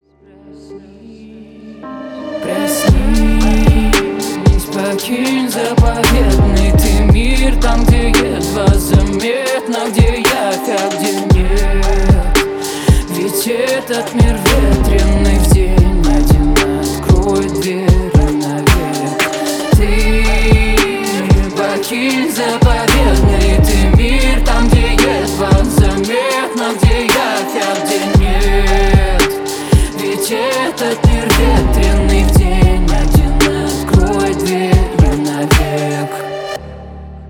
• Качество: 320, Stereo
атмосферные
Trap
басы
красивый женский голос